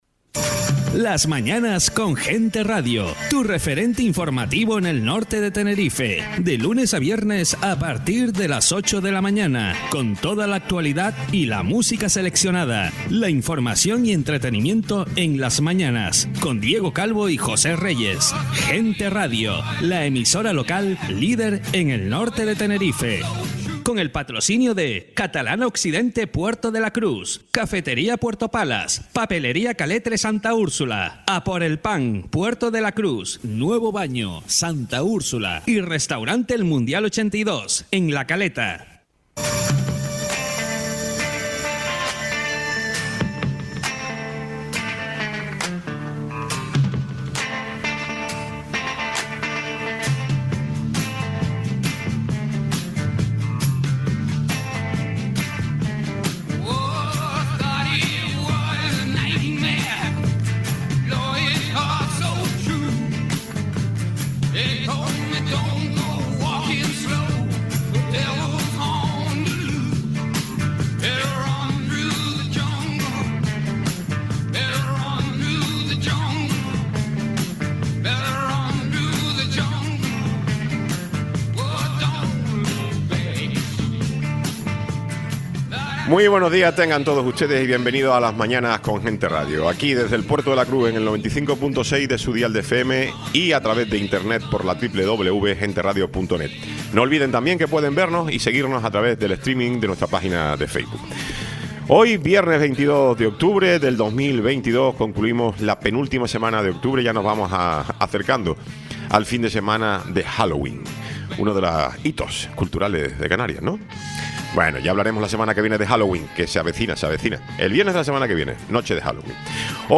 Tiempo de entrevista